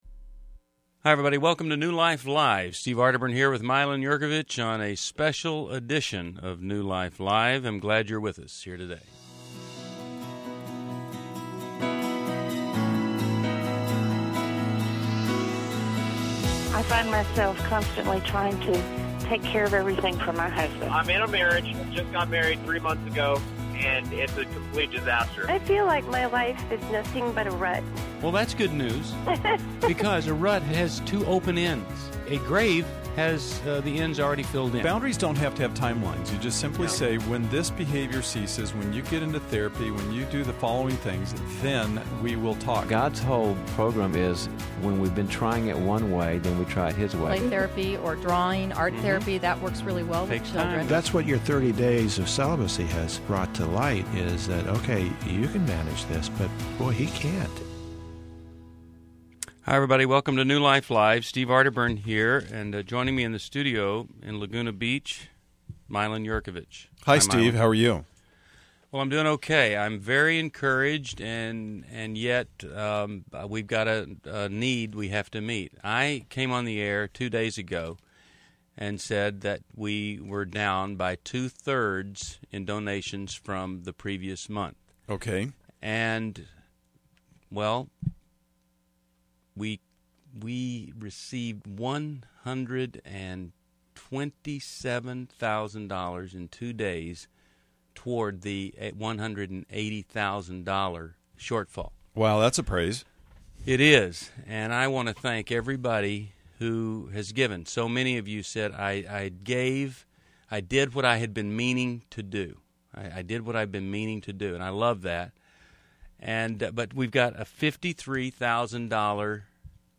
Caller Questions: Why I want to keep New Life Live on the air!